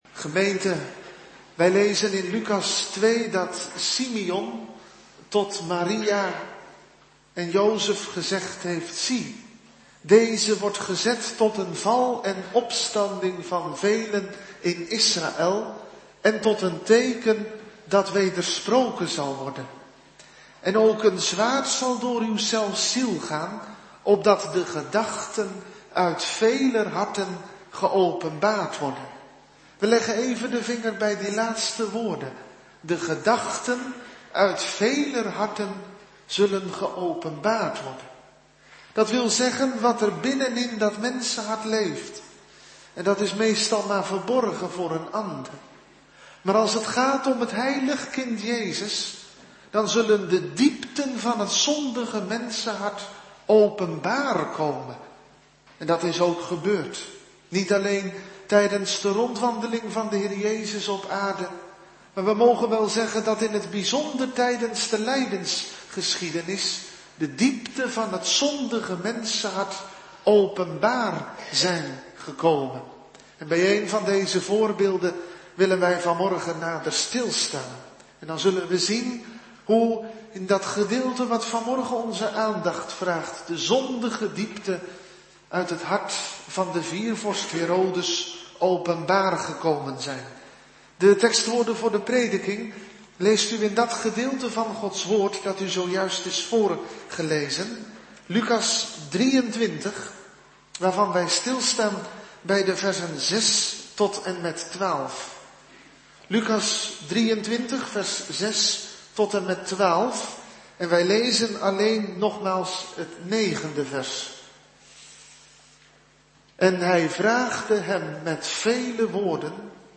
Soort Dienst: Bezinningsuur op het Heilig Avondmaal